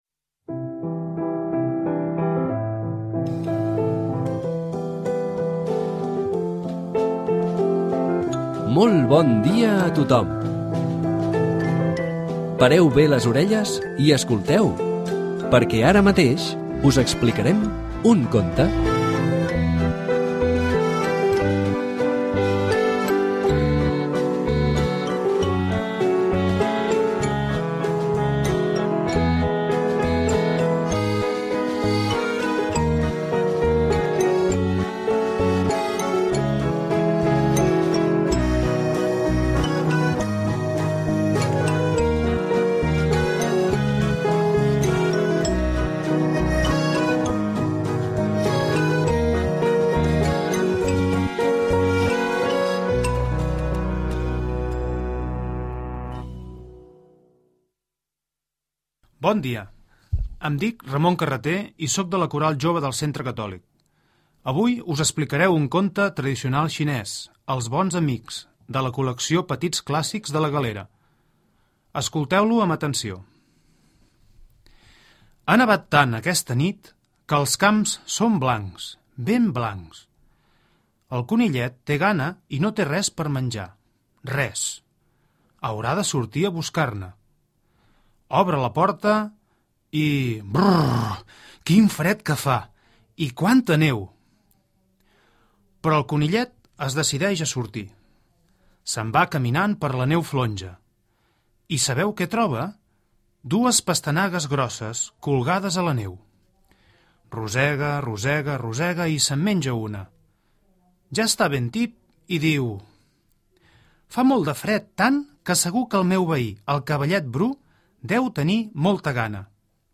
Àudio del conte: